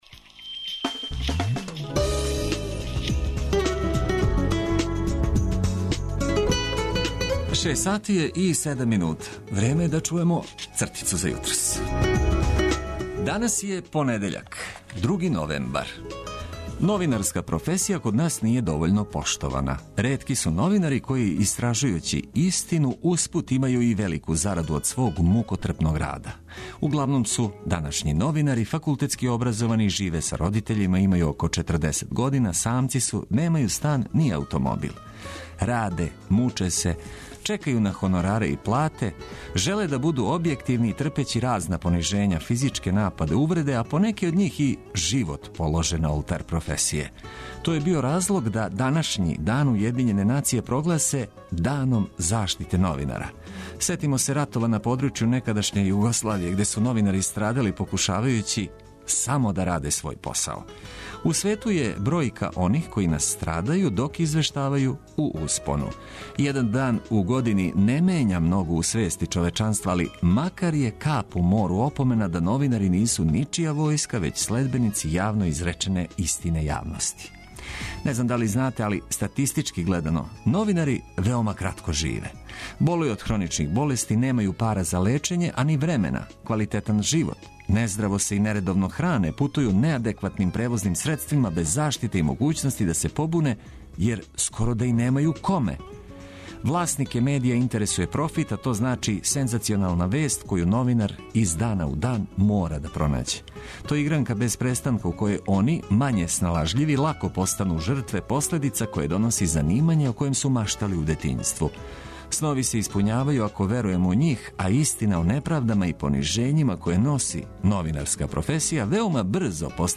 Будимо вас и припремамо за нов нерадни дан уз много добре музике и лепих прича.